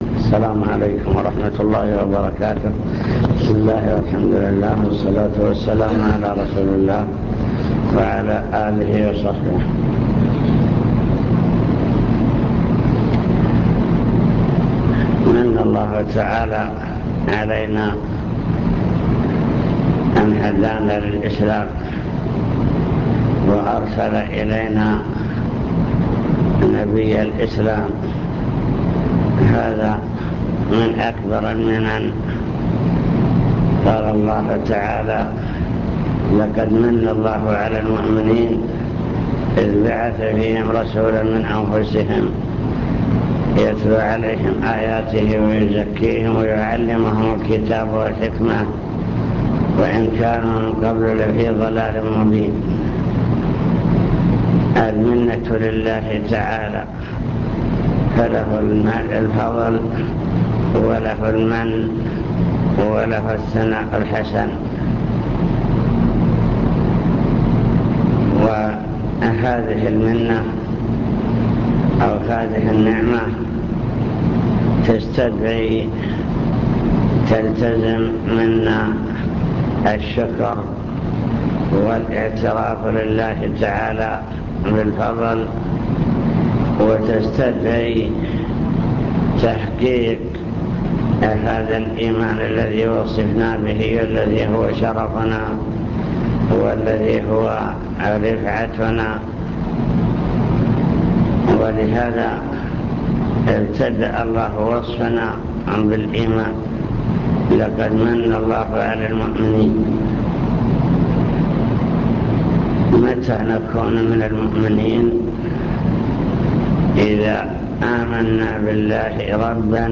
المكتبة الصوتية  تسجيلات - محاضرات ودروس  محاضرة في الدفاع المدني طرق تحقيق الإيمان